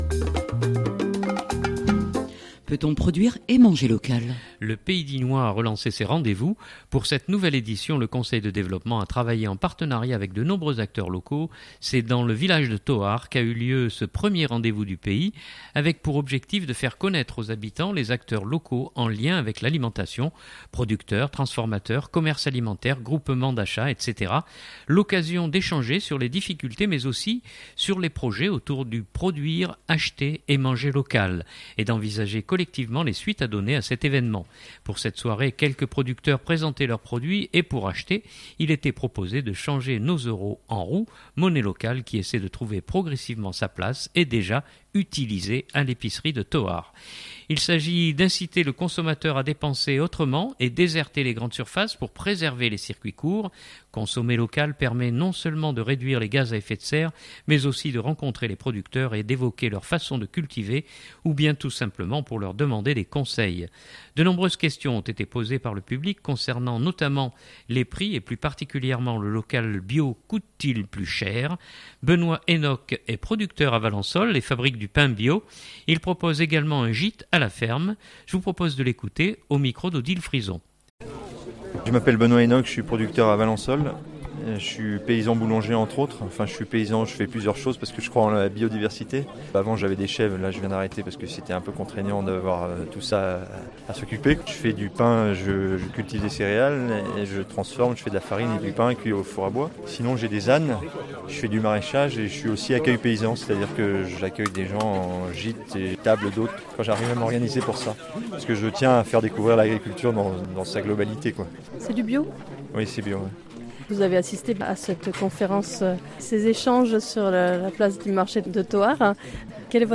C’est dans le village de Thoard qu’a eu lieu ce 1er Rendez-vous avec pour objectif de faire connaître aux habitants les acteurs locaux en lien avec l’alimentation (producteurs, transformateurs, commerces alimentaires, groupements d’achats...).